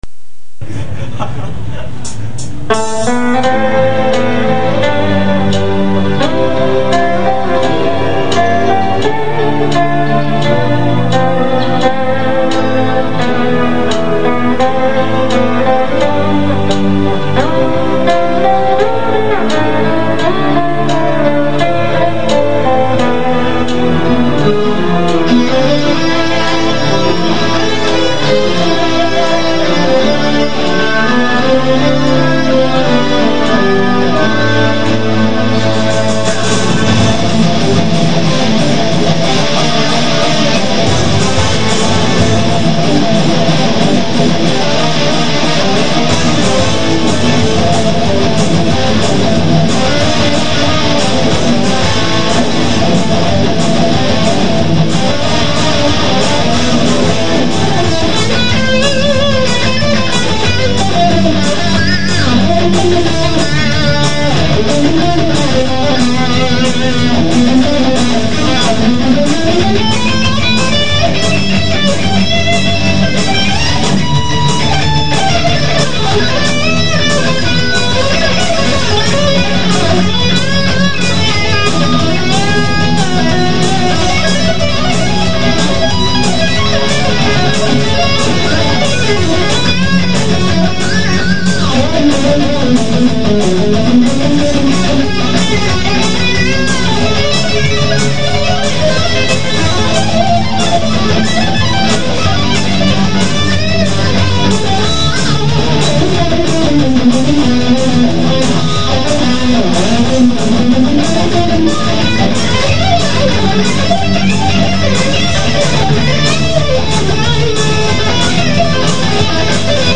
(東京大学駒場祭2003　ＳＨＫライブより)
後半のアドリブではパニクってコード進行が分からなくなり
ほとんど勘で弾いてます。